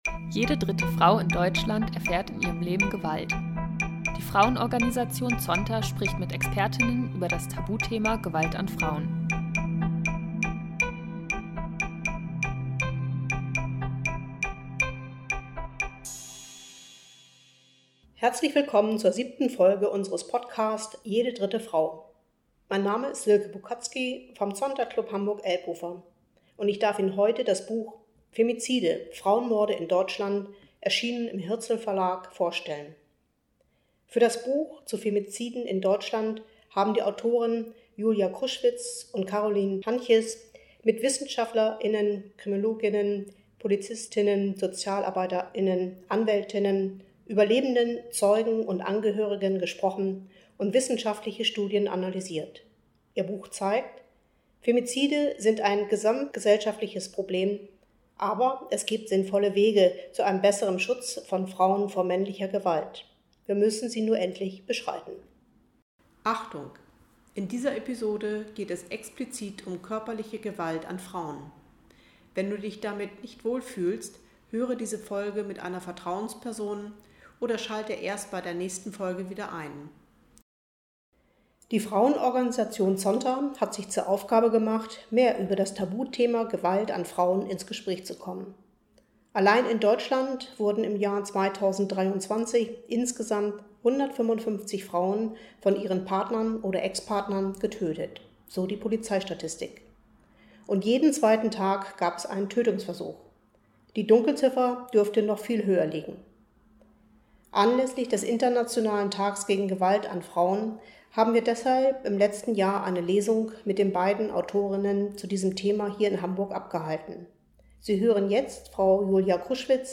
Folge 07 | Femizide - Frauenmorde in Deutschland | Live-Lesung ~ JEDE DRITTE FRAU Podcast